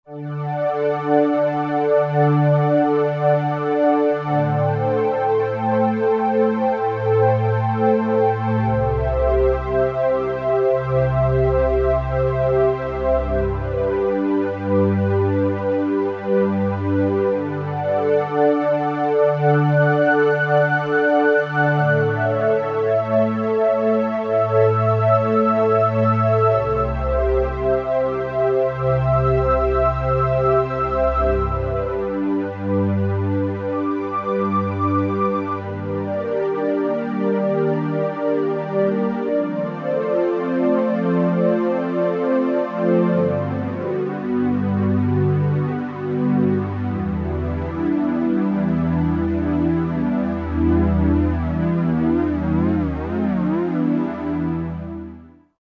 Roland S-760 audio demos